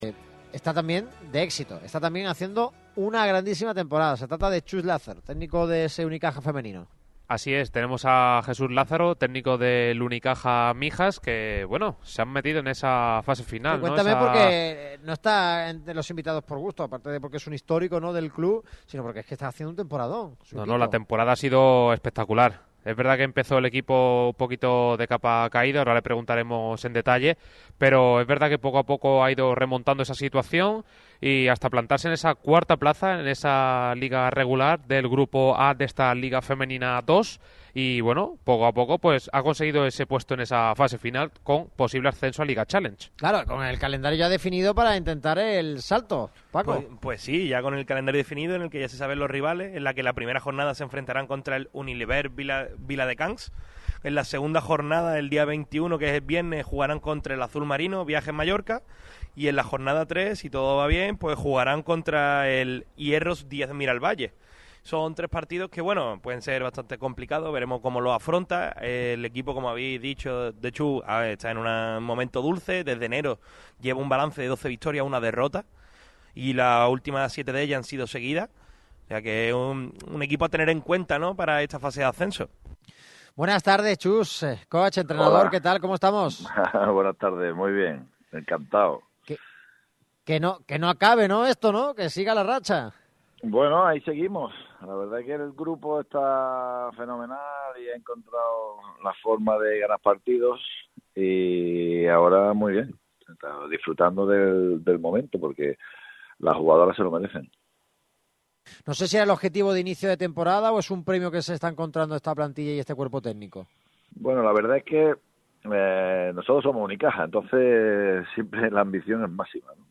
ha atendido la llamada de Radio MARCA Málaga.